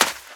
STEPS Sand, Run 05.wav